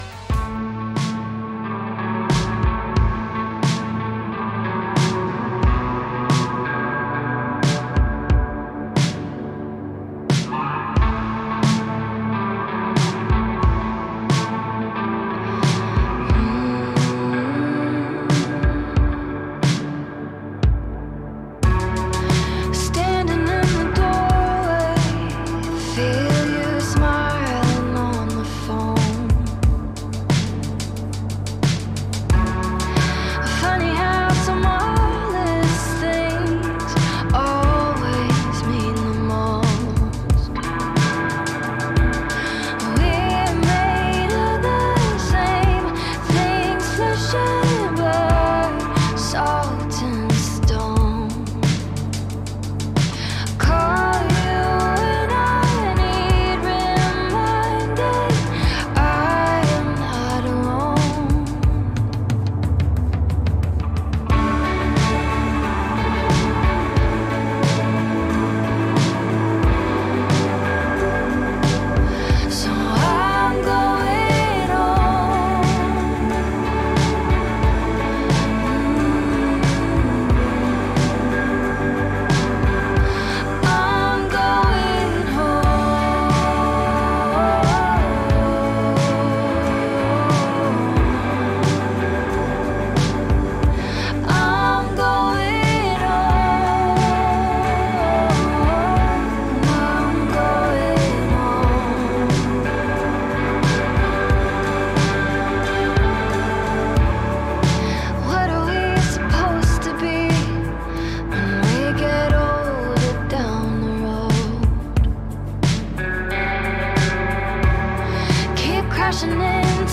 WARNING: Loud -
I recorded it on audacity but the quality is not great so I'd love to find the actual artist.